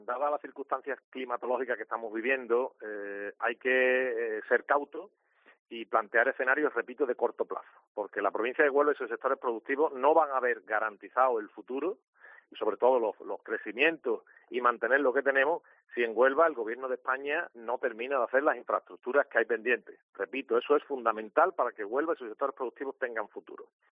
Álvaro Burgos, delegado de Agricultura, Pesca, Agua y Desarrollo Rural